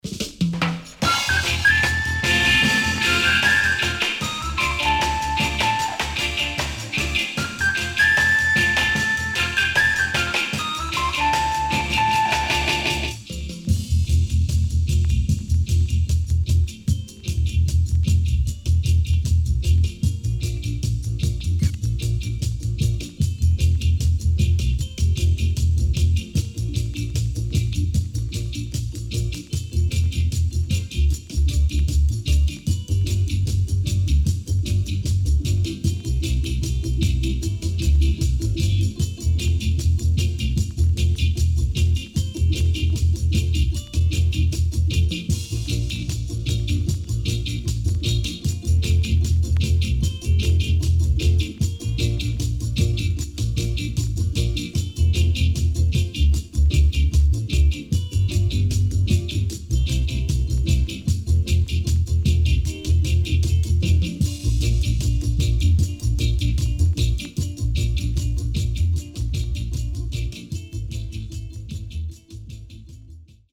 SIDE A:うすいこまかい傷ありますがノイズあまり目立ちません。